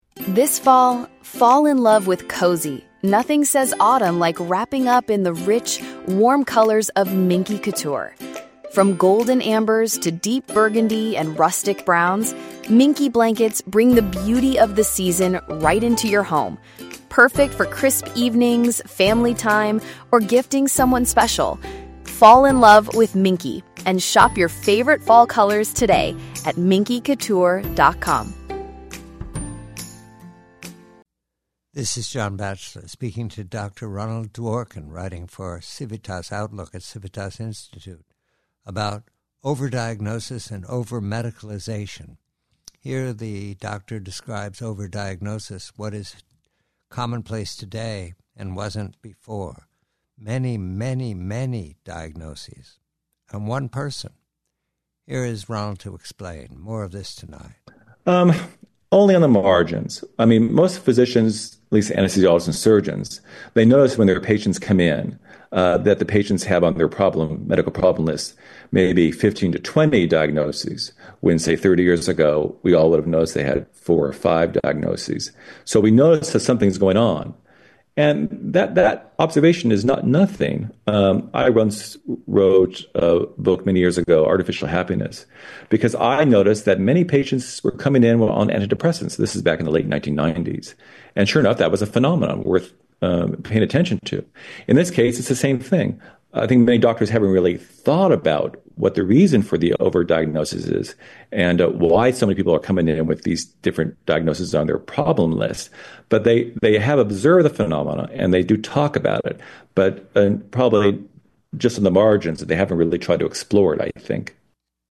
Preview: Conversation